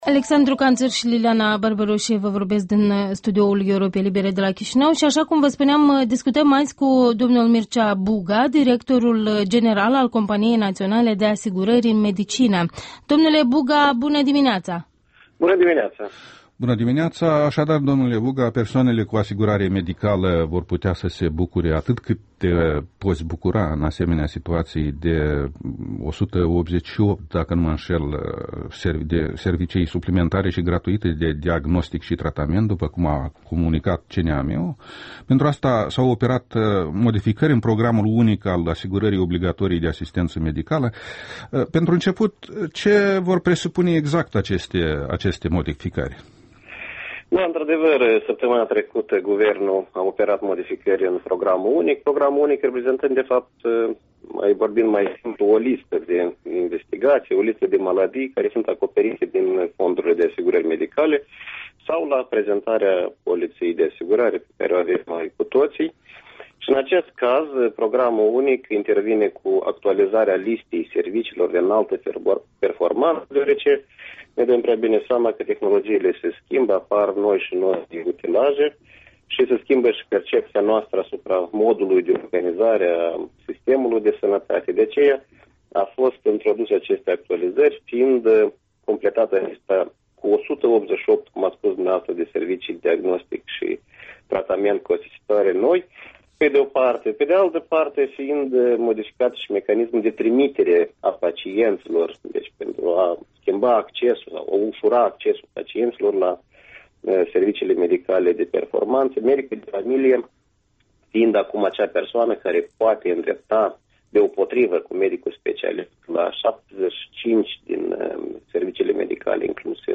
Interviul matinal la Europa Liberă: cu Mircea Buga